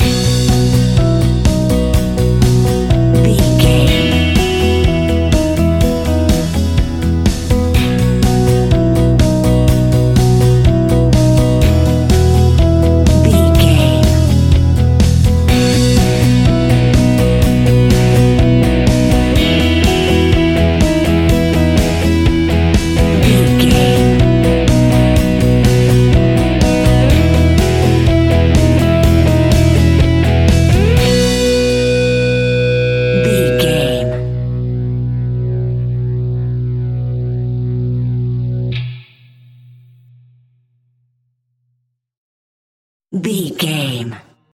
Ionian/Major
indie pop
fun
energetic
uplifting
cheesy
instrumentals
guitars
bass
drums
piano
organ